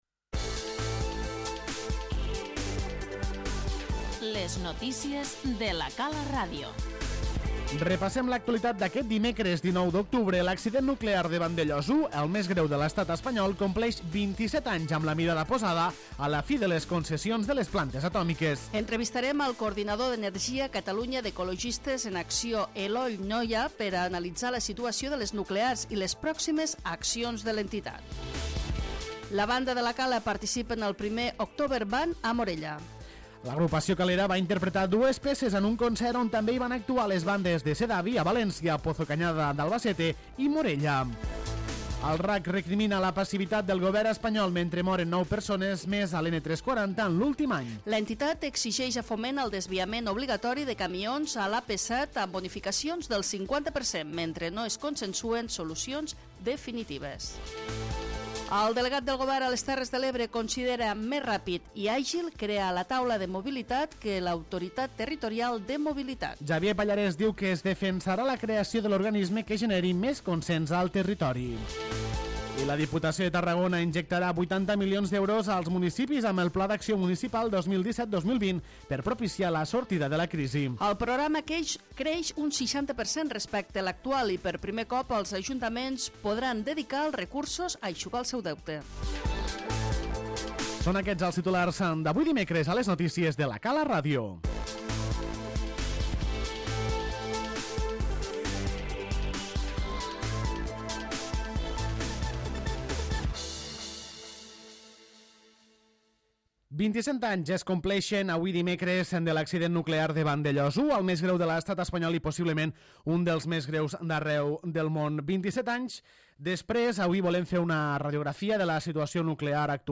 Entrevistarem